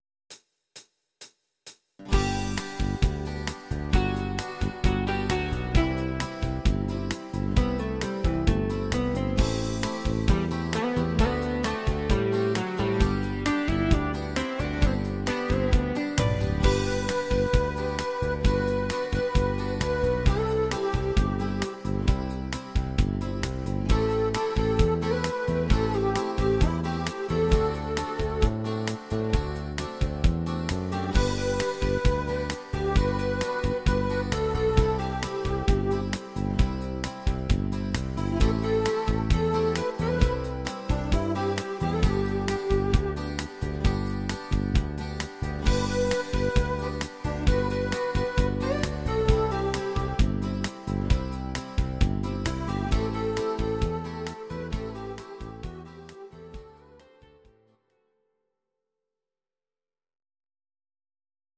These are MP3 versions of our MIDI file catalogue.
Your-Mix: Rock (2970)